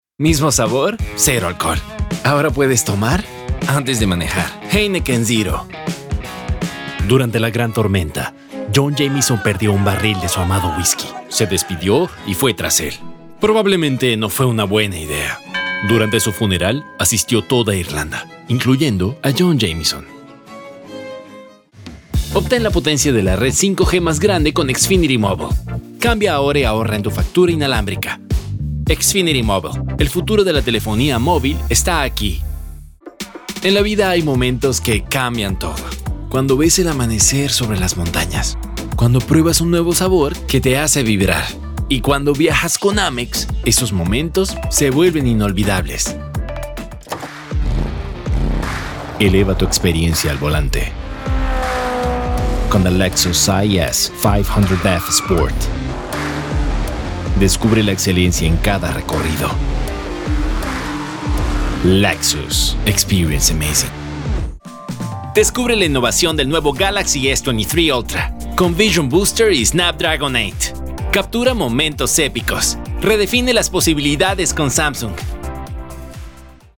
Spanish Commercial